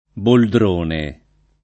[ boldr 1 ne ]